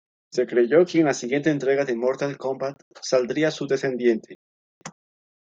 des‧cen‧dien‧te
/desθenˈdjente/